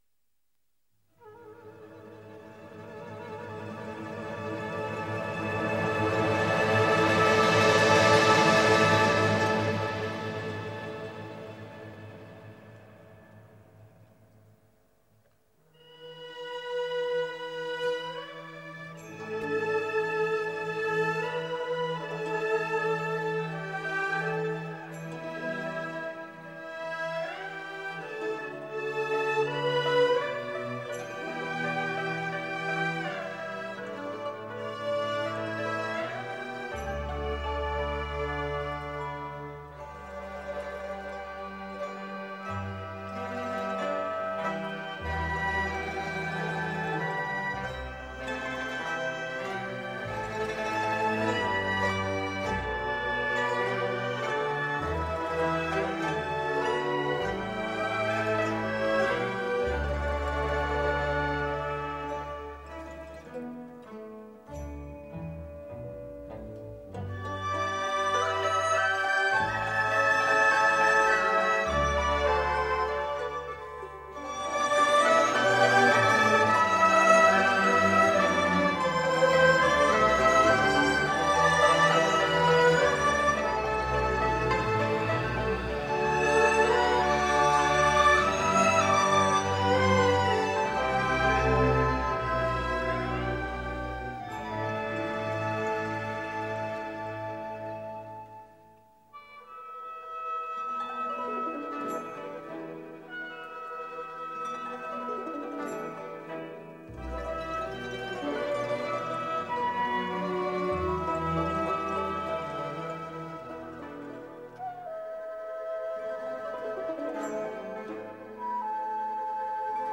合奏